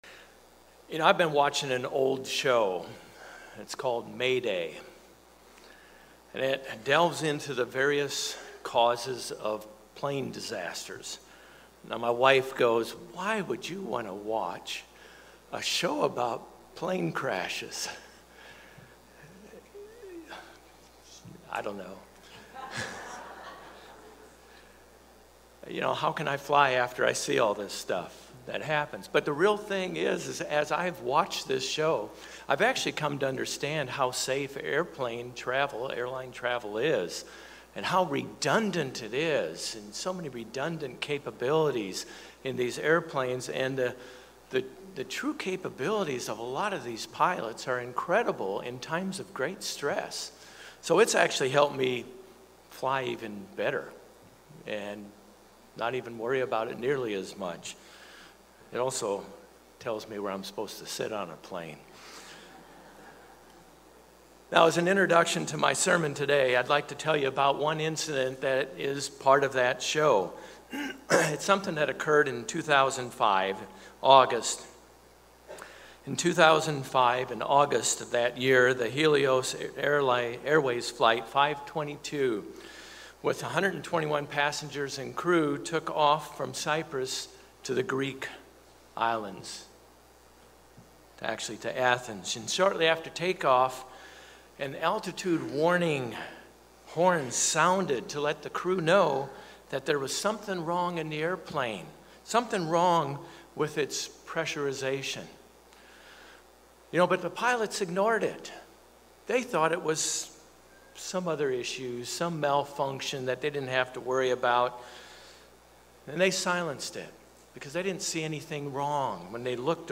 The Apostle Paul talks often about the subject of the conscience and its importance in our actions toward God and our fellow brothers and sisters. In this sermon we will review several scriptures in the Bible concerning the conscience and three points in having a godly conscience.